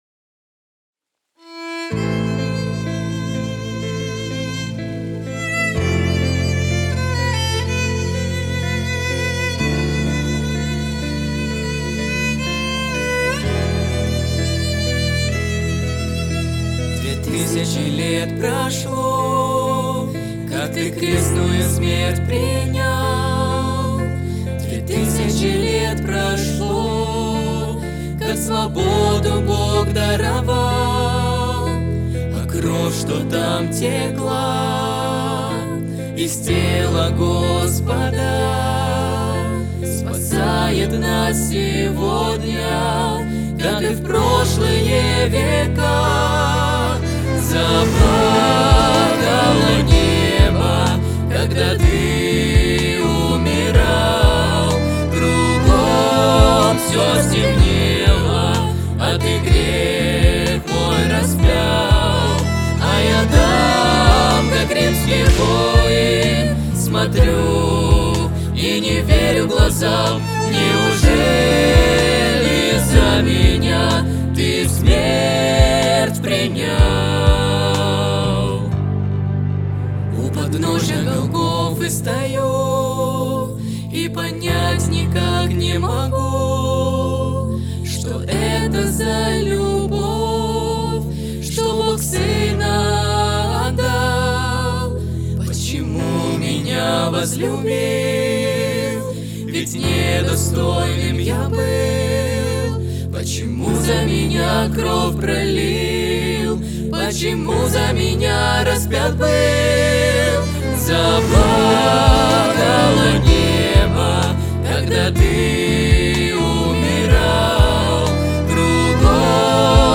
908 просмотров 373 прослушивания 37 скачиваний BPM: 125